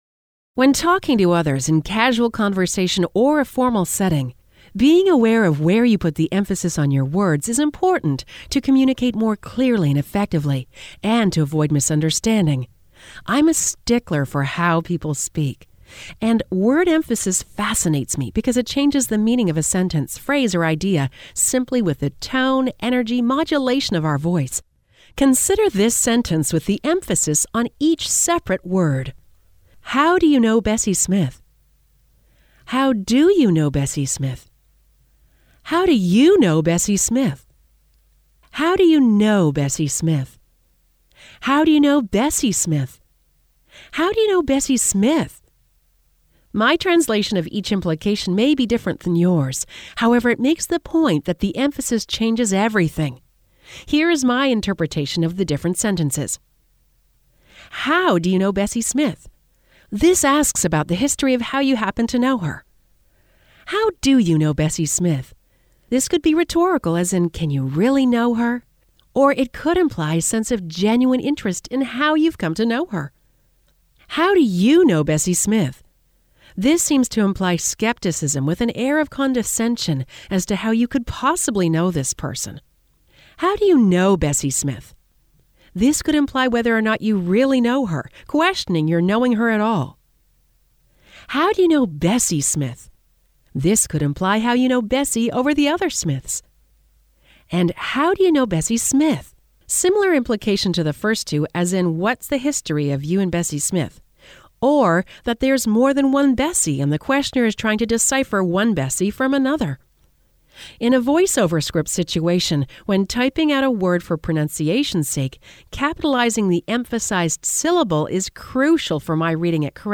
(audio version of blog below)